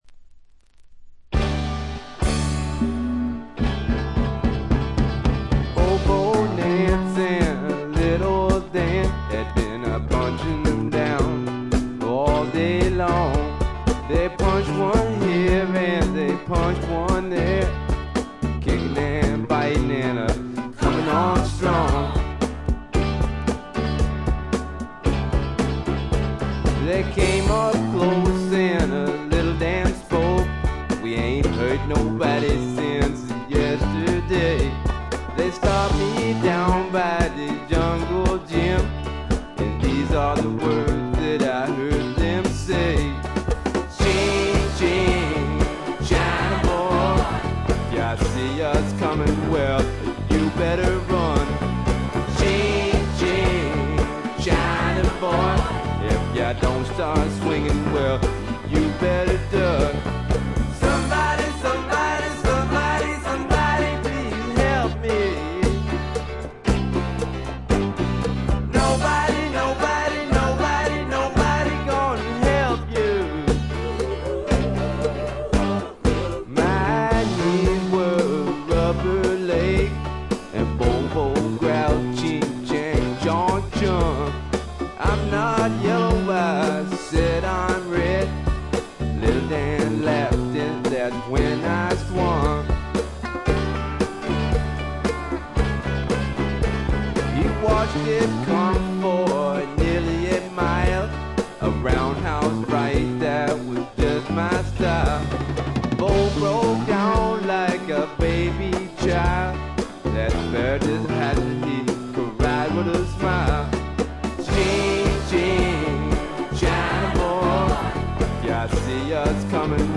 微細なバックグラウンドノイズのみでほとんどノイズ感無し。
よりファンキーに、よりダーティーにきめていて文句無し！
試聴曲は現品からの取り込み音源です。